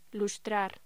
Locución: Lustrar
voz